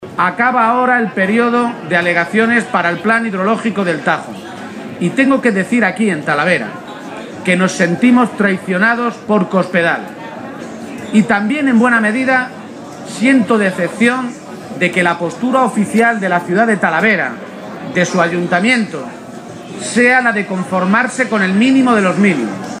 García-Page se pronunciaba de esta manera este mediodía, en una comparecencia ante los medios de comunicación durante su visita a la Feria de Talavera.